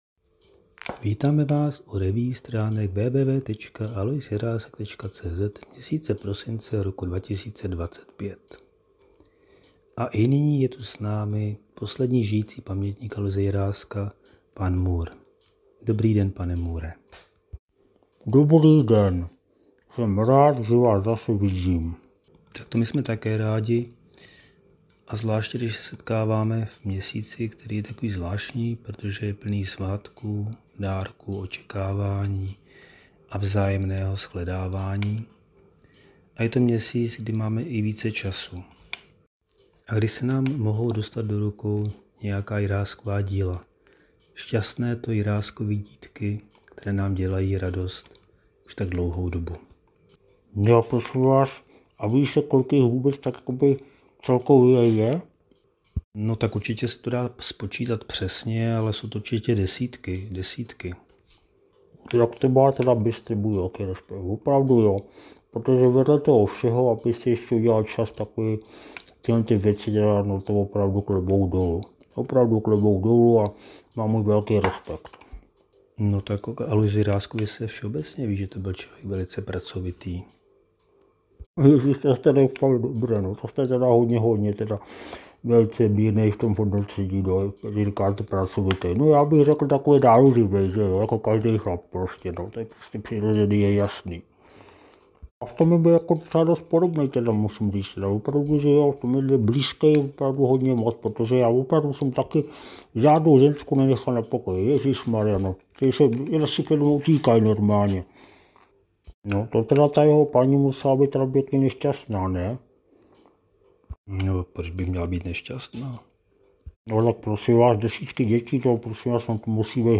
Poslední letošní amatérský pokus o zvukovou revue s obvyklou humornou nadsázkou přináší rázovitou vzpomínku našeho fiktivního posledního žijícího pamětníka Aloise Jiráska.